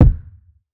Flame Kick.wav